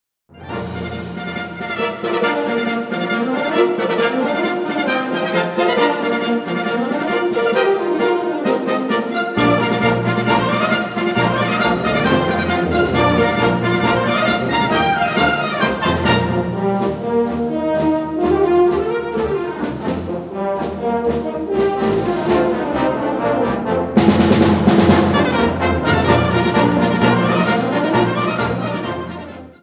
Instrumentation Ha (orchestre d'harmonie)